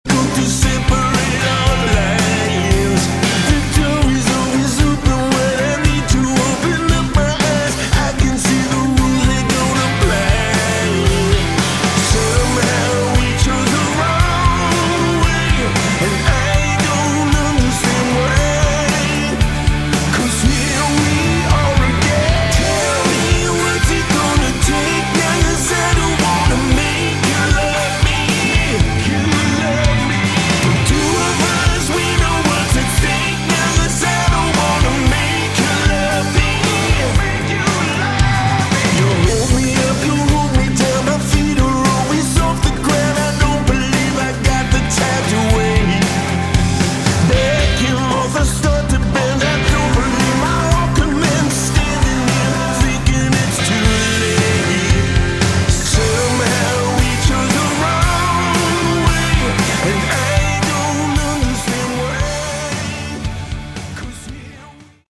Category: AOR / Melodic Rock
lead vocals
guitars